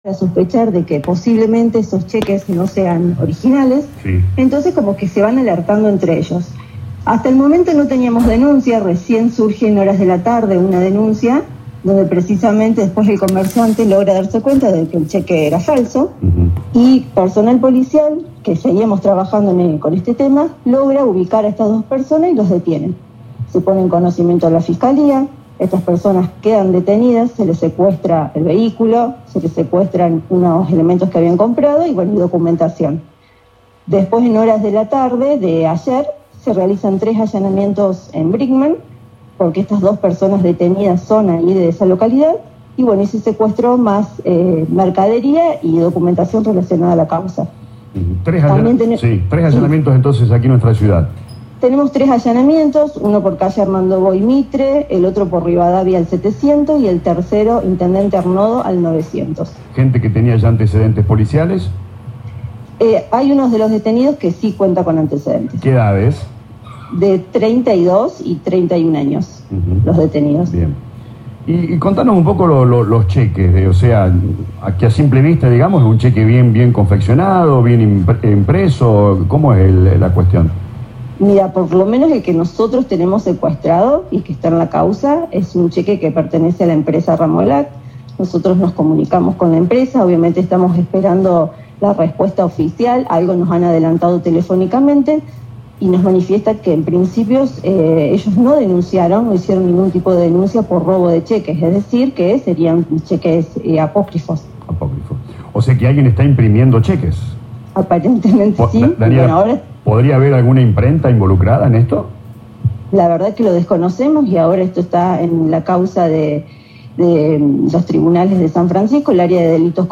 En diálogo con LA RADIO 102.9